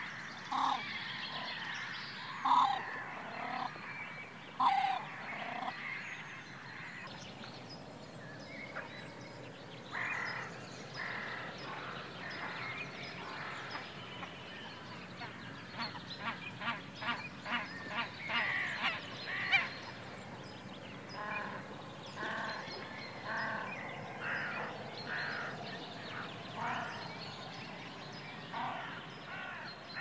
Photos de Grèbe huppé - Mes Zoazos
grebe-huppe.mp3